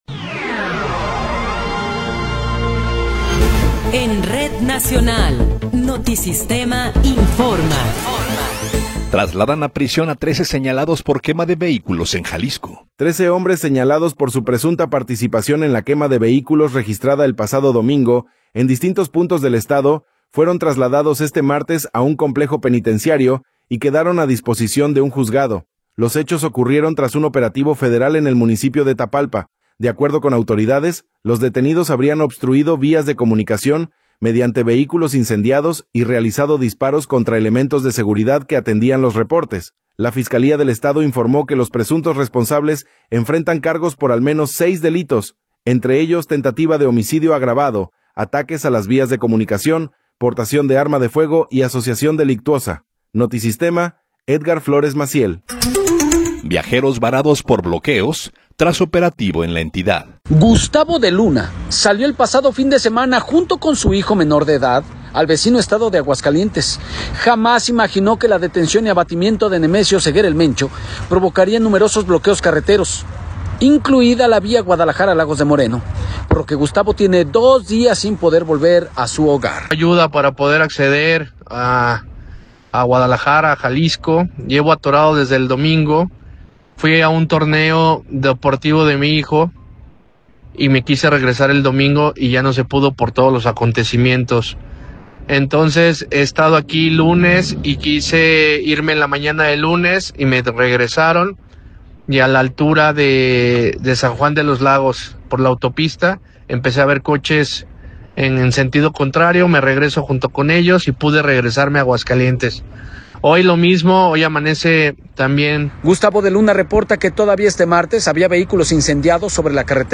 Noticiero 19 hrs. – 24 de Febrero de 2026